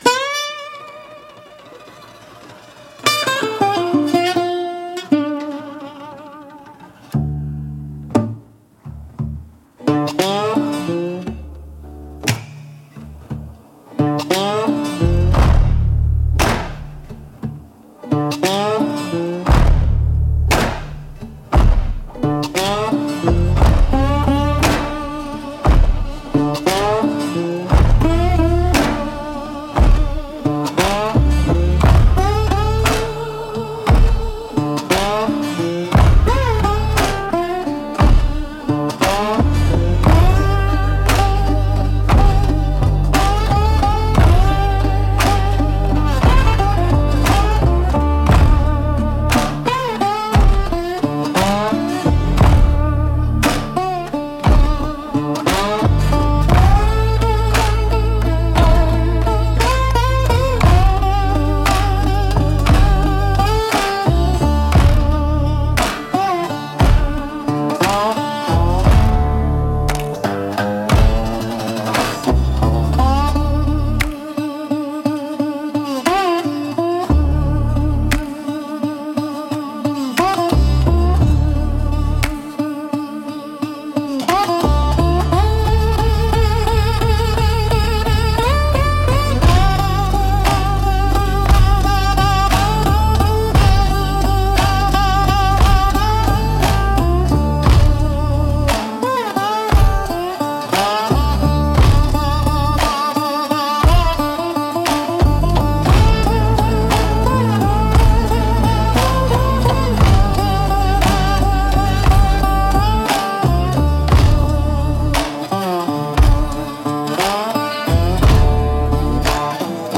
Instrumental - Ghost in the Gears 2.53